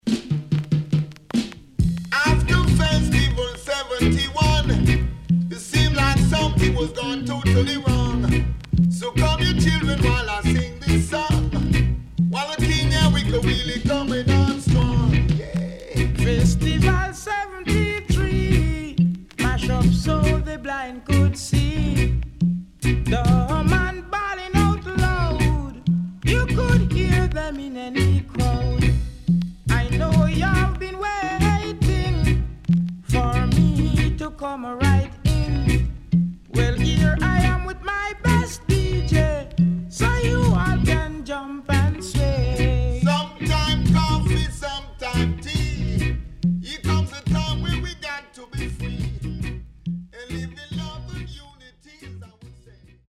CONDITION SIDE A:VG(OK)